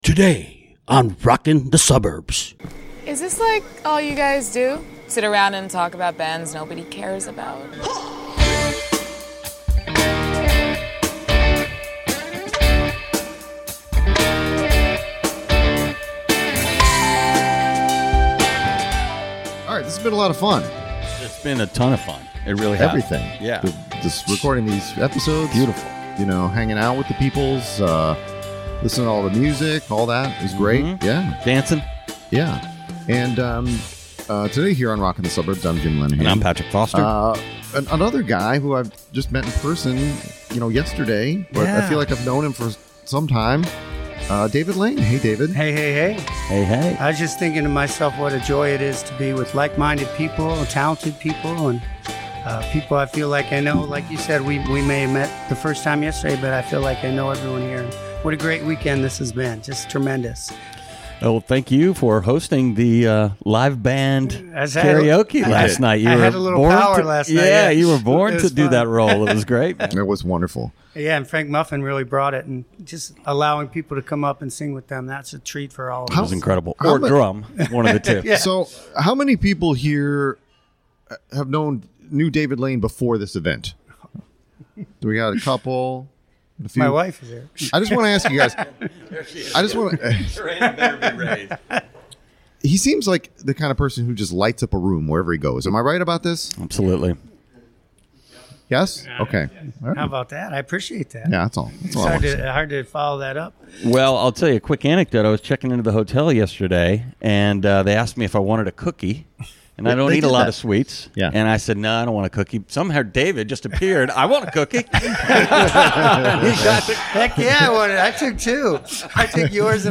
Recorded at Suburbs Fest Midwest: Part 8
A crowd gathered at Arlington Ale House to join us and contribute.